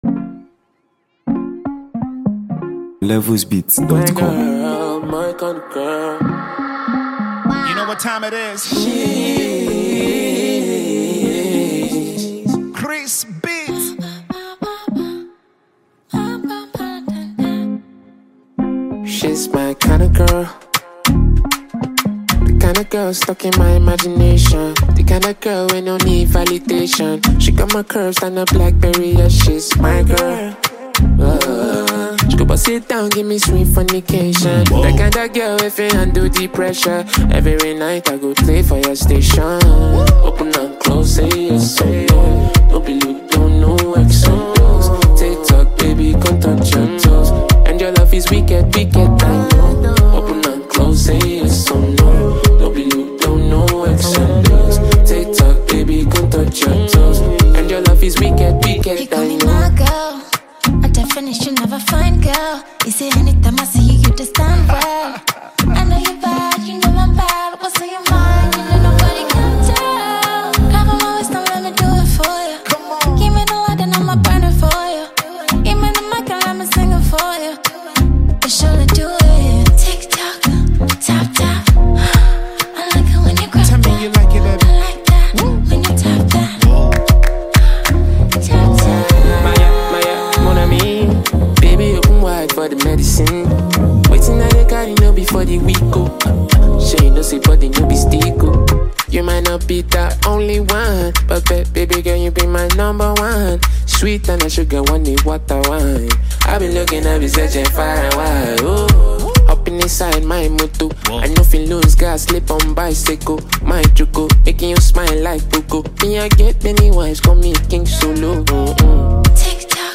Nigeria Music 2025 2:39
a sensational female singer-songwriter